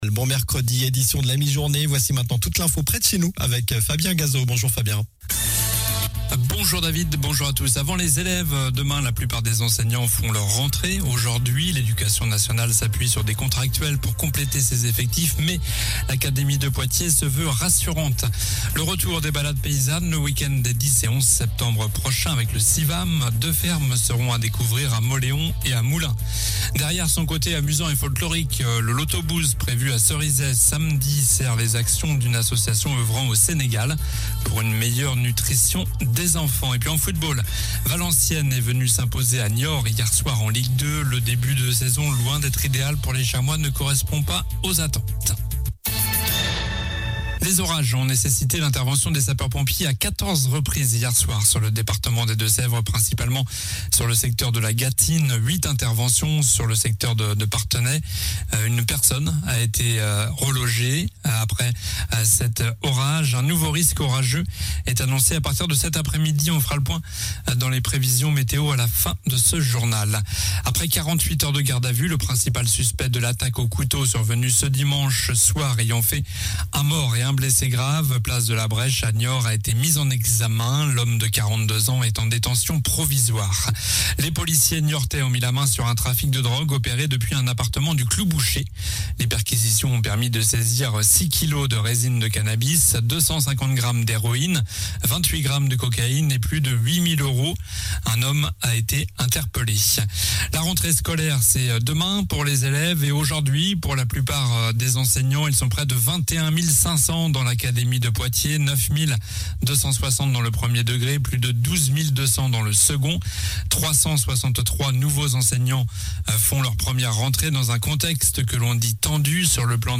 Journal du mercredi 31 août (midi)